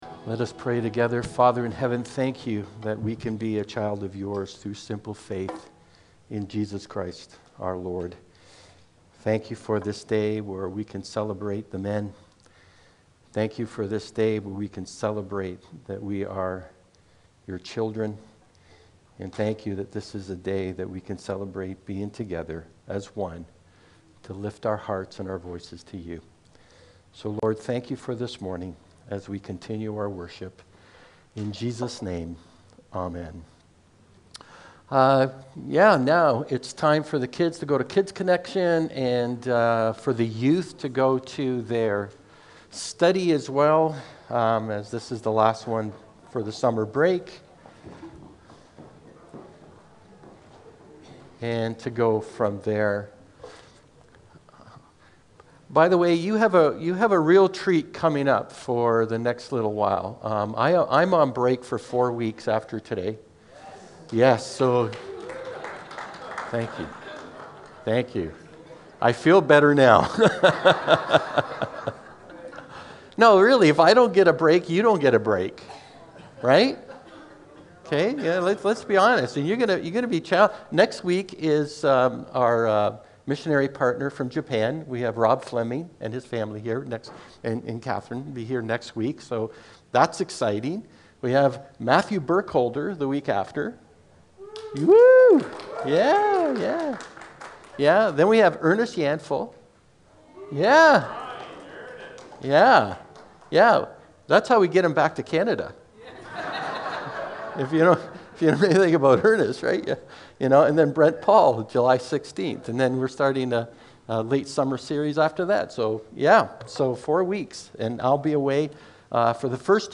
2023-06-18-Sermon.mp3